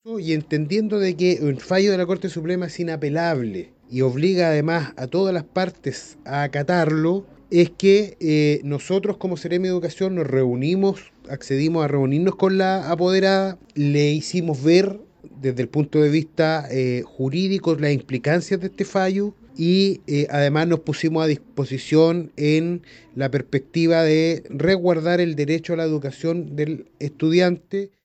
El seremi de Educación, Carlos Benedetti, argumentó que el fallo de la Corte Suprema es inapelable y por ende obliga a todas las partes a acatarlo.
seremi-educacion-1.mp3